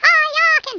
Speech
FirePunch.wav